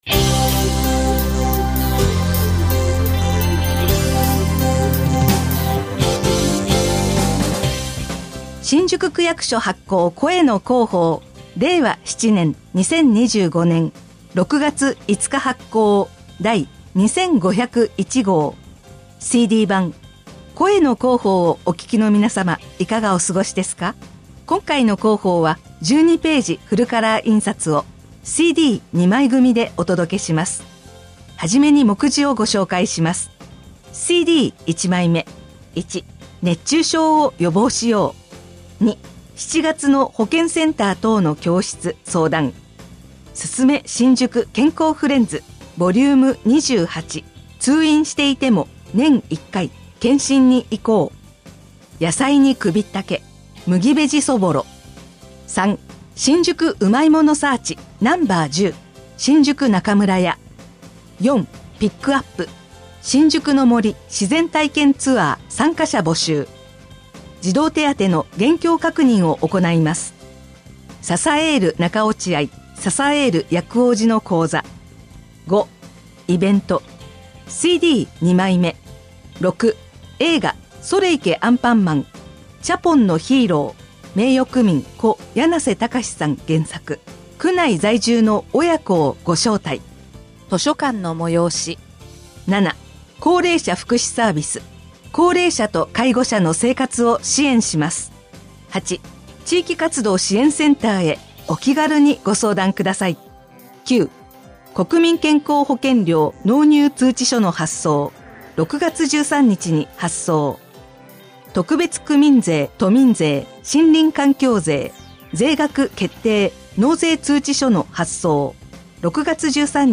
声の広報（目次） ［MP3形式：5.00MB］（新規ウィンドウ表示） (1)CD1枚目（熱中症を予防しよう！